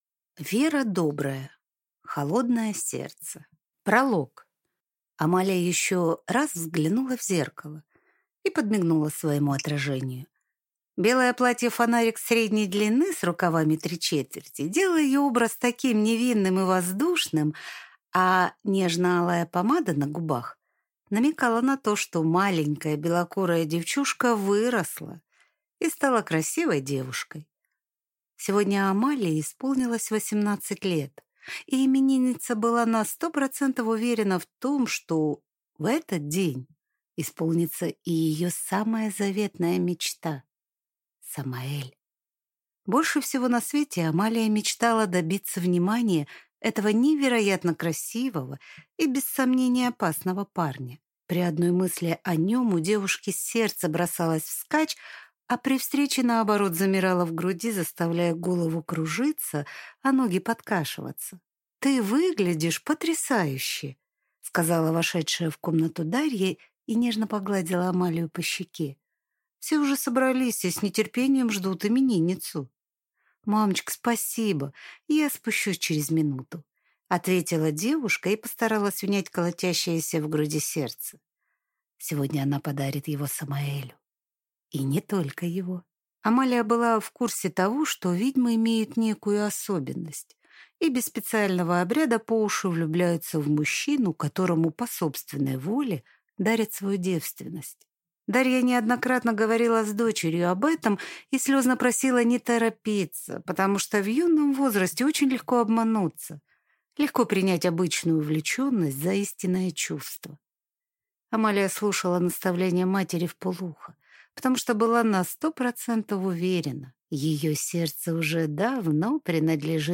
Аудиокнига Холодное сердце | Библиотека аудиокниг
Прослушать и бесплатно скачать фрагмент аудиокниги